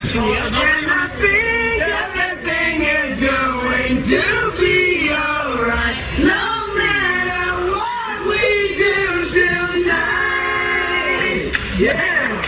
~Song Clips~